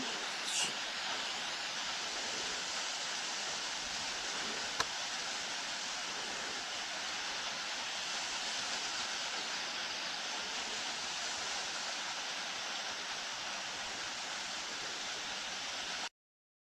Piculus rivoliiCrimson-mantled WoodpeckerCarpintero CandelaPic de Rivoli